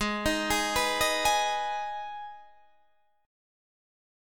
Abm chord